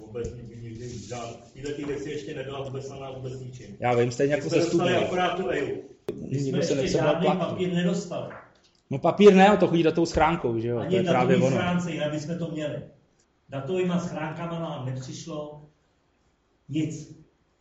Veřejné zasedání zastupitelstva obce 7. listopadu 2016
Další veřejné zasedání se uskutečnilo v pondělí 7.11.2016 od 19:00.